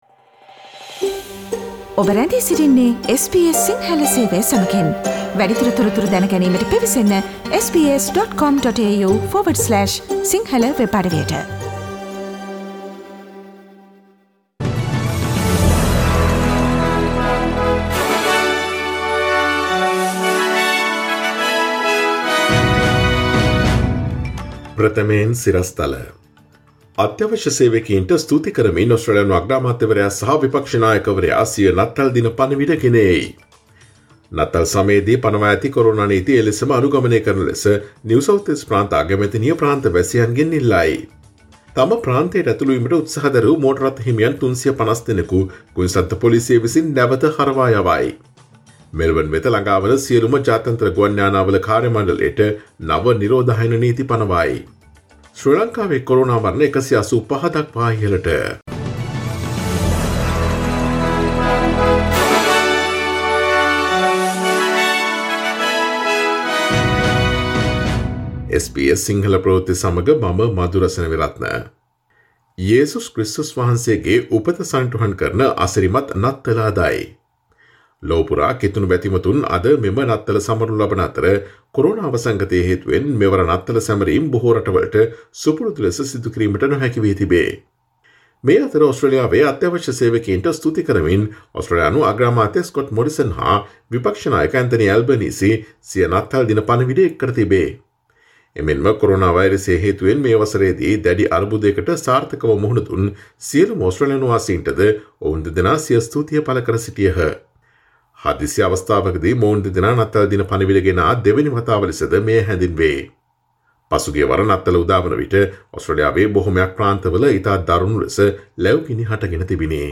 Today’s news bulletin of SBS Sinhala radio – Friday 25 December 2020